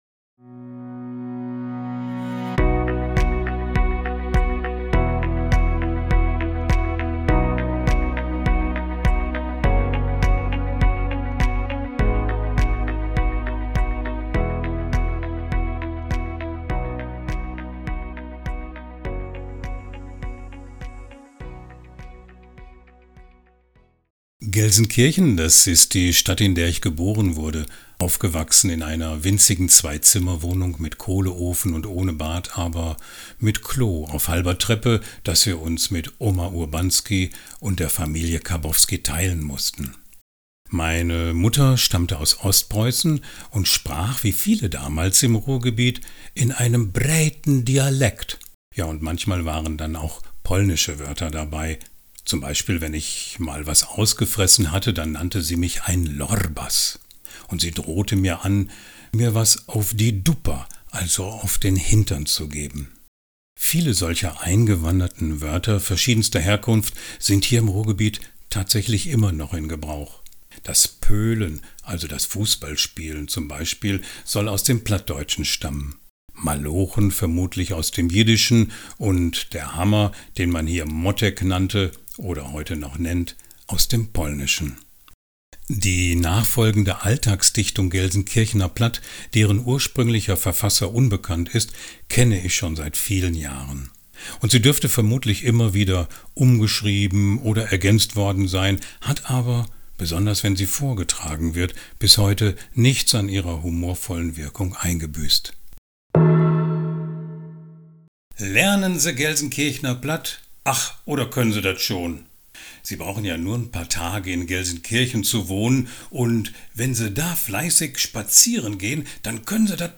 Aber deine Stimme oder Aussprache ist ja dialektfrei – für mich jedenfalls exzellent zu verstehen.